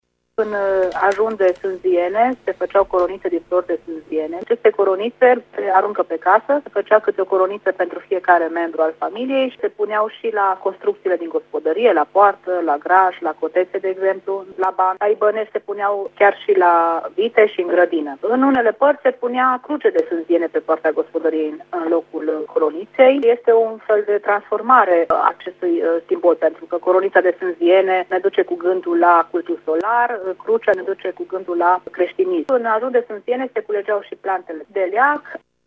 muzeograful